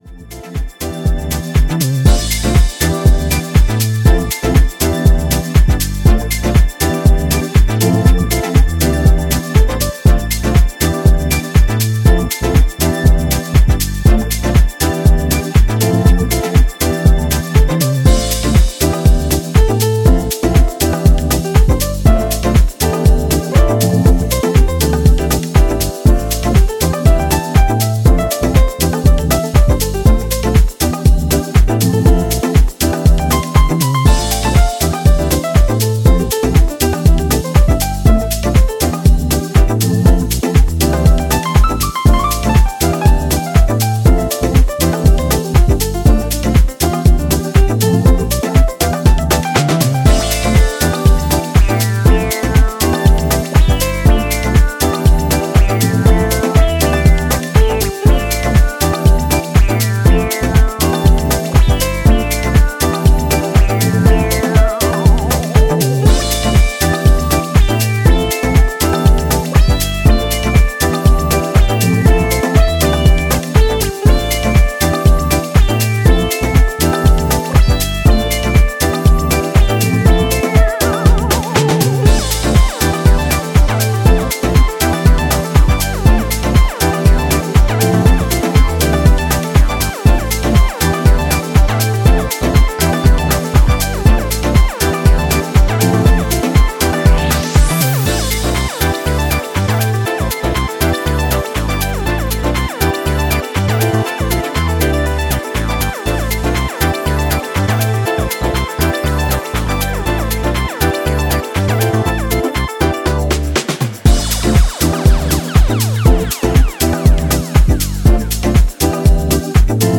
is a multi-layered and complex
secret house weapon filled with piano solos and lead riffs.